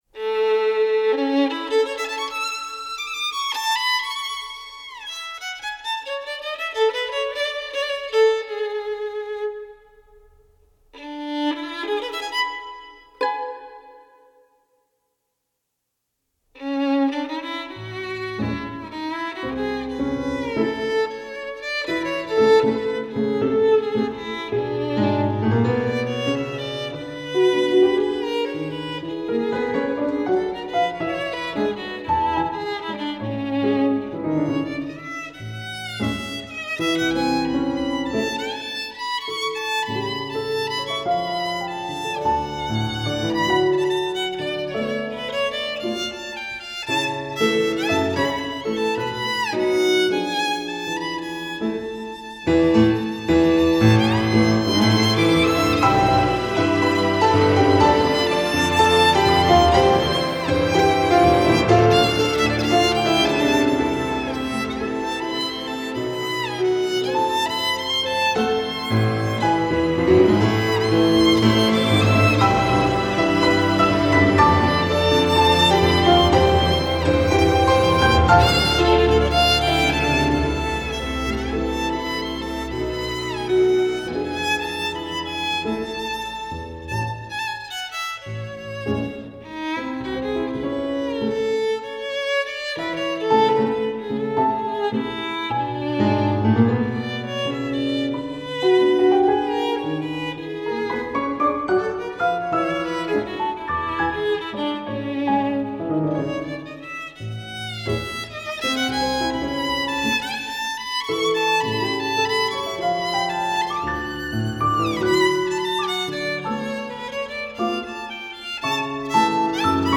張力十足的探戈樂曲
小提琴
鋼琴